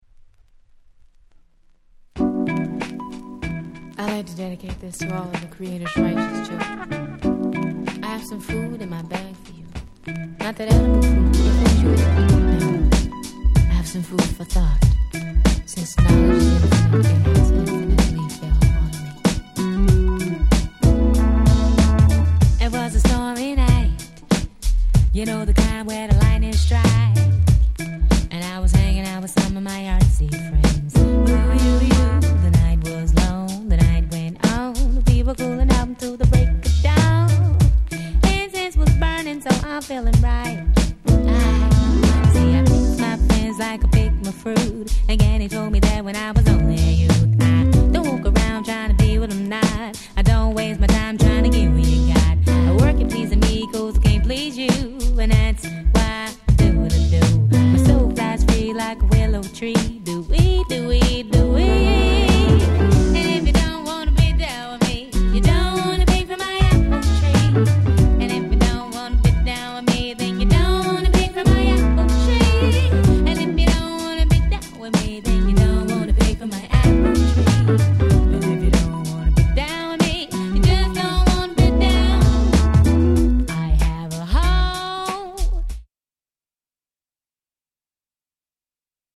90's R&B Classic !!